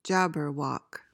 PRONUNCIATION:
(JAB-uhr-wok)